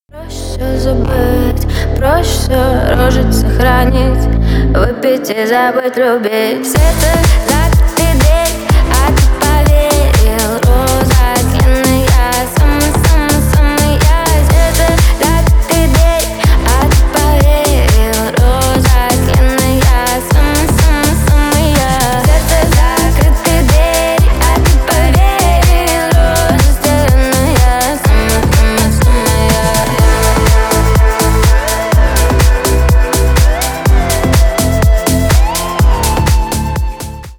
• Качество: 320 kbps, Stereo
Ремикс
клубные